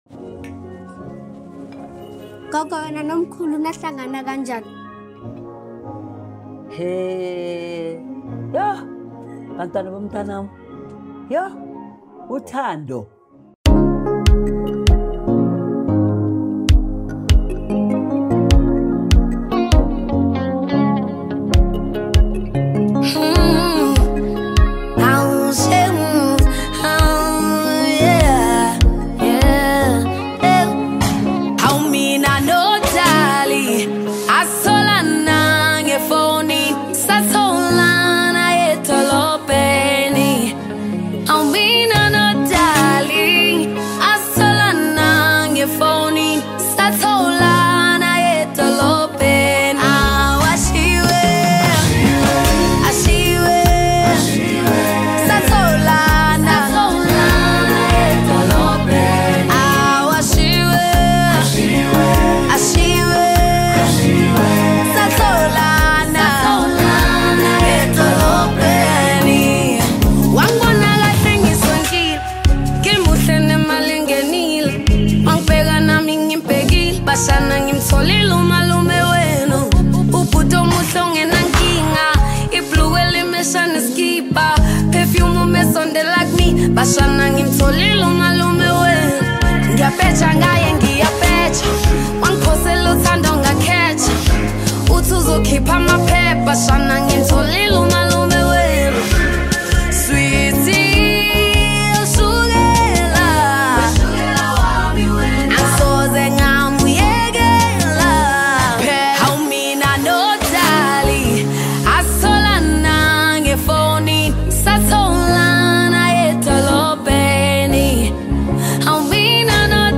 Home » Amapiano » DJ Mix » Maskandi